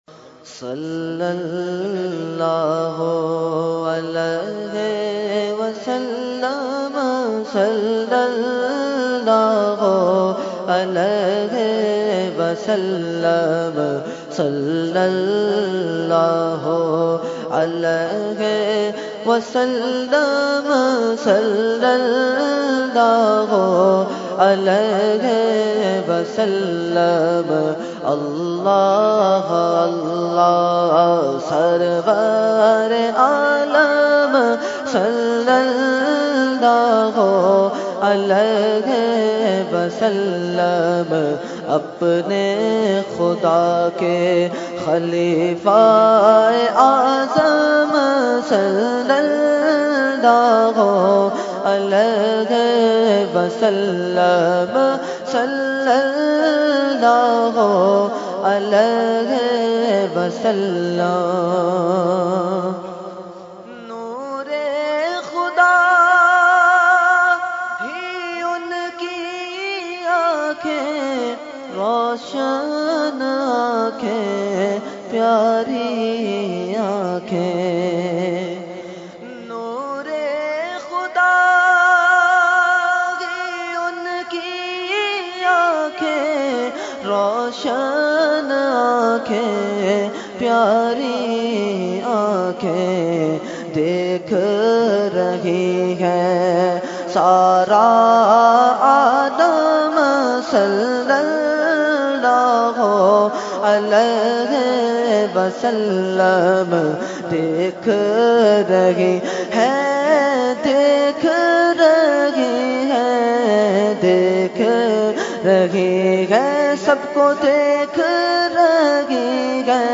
Category : Naat | Language : UrduEvent : Urs Qutbe Rabbani 2016